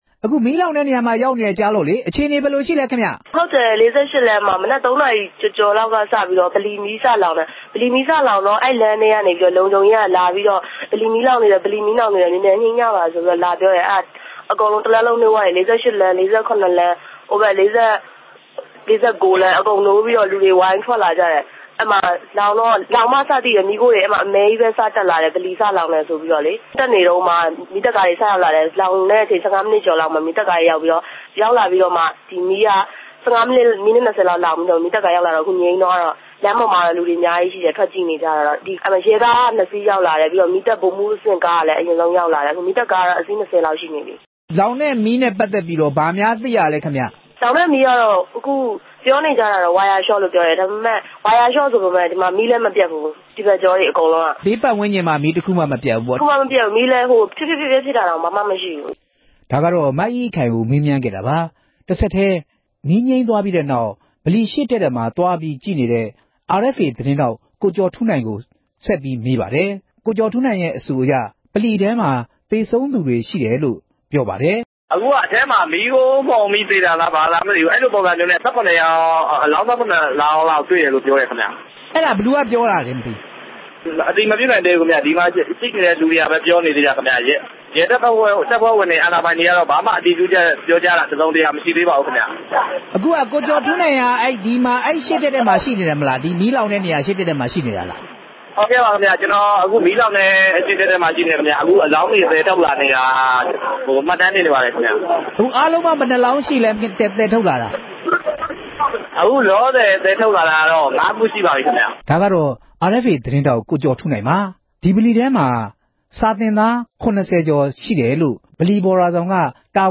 အခင်းဖြစ်ပွားရာနေရာကို ရောက်ရှိနေတဲ့ သတင်းထောက်များနဲ့ ဆက်သွယ်မေးမြန်းချက်